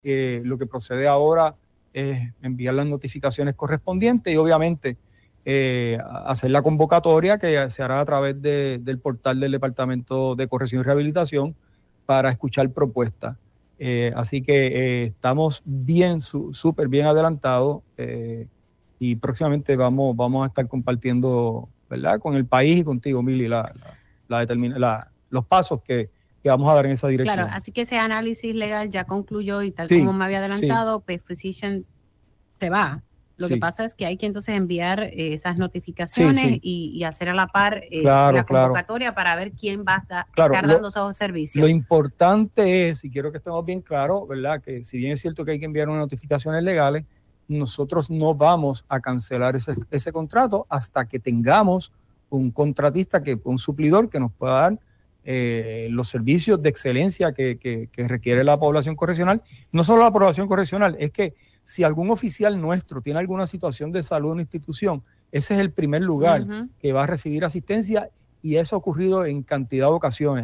El secretario del Departamento de Corrección y Rehabilitación (DCR), Francisco Quiñones afirmó en Pega’os en la Mañana que ya han enviado notificaciones de incumplimiento de servicio a la empresa médica Physician Correctional.